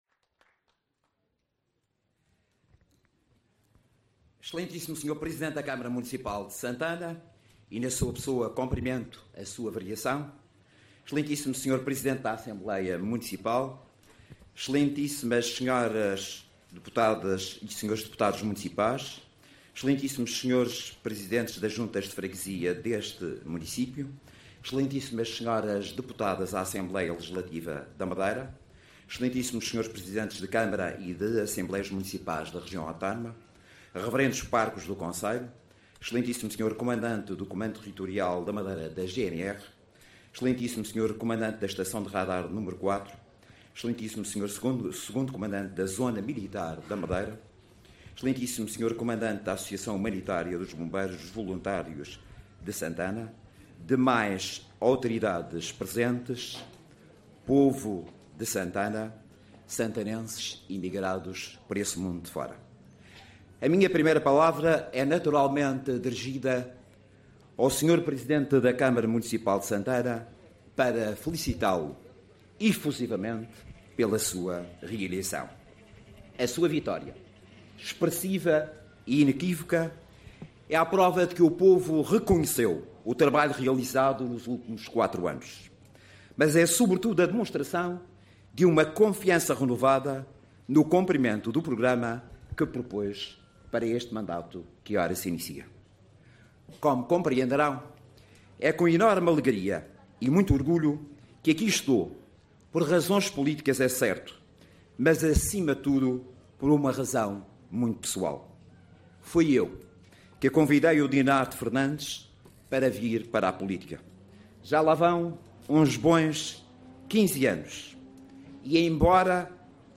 As garantias de José Manuel Rodrigues foram deixadas na cerimónia de tomada de posse da Câmara Municipal de Santana.